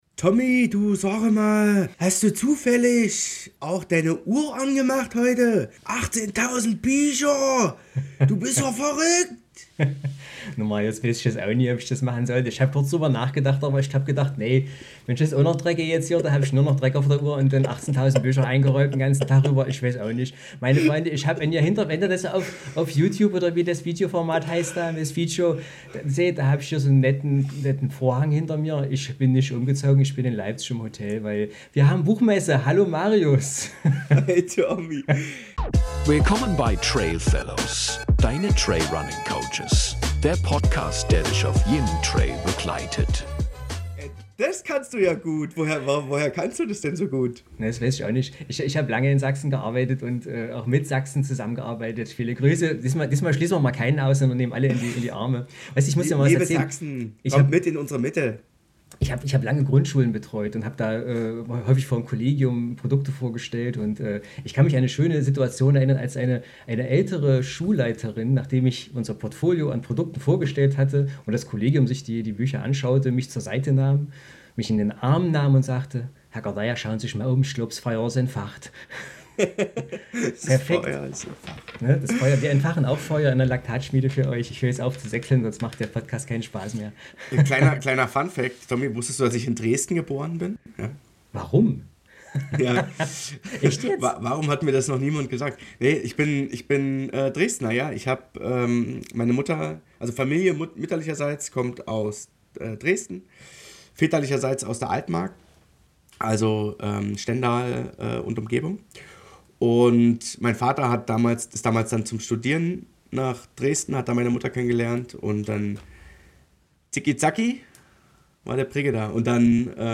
In diesem Podcast-Interview tauchen wir tief in die Welt des Intervalltrainings ein.